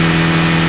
1 channel
MOTOR3.mp3